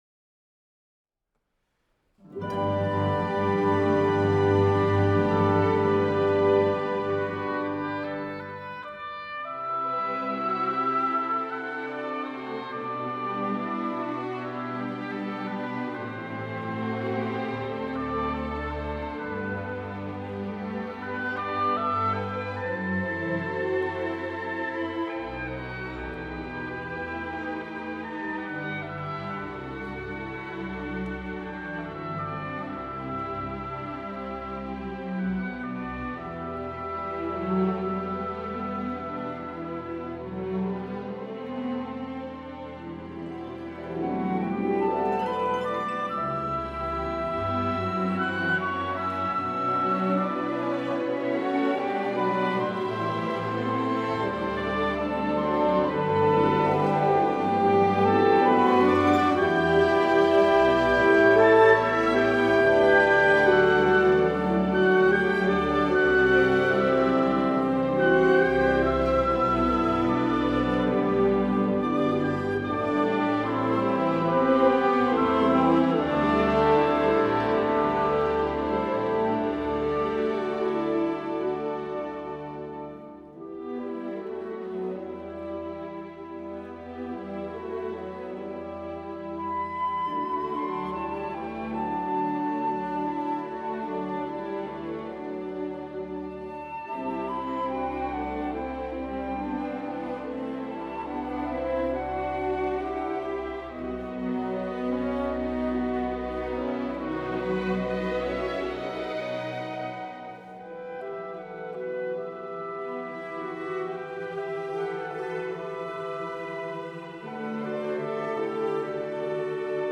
Klasika